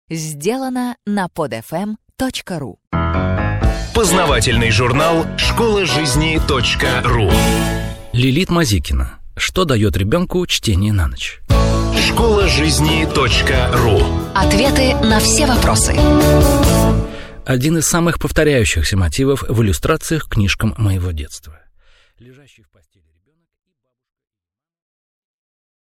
Аудиокнига Что даёт ребёнку чтение на ночь?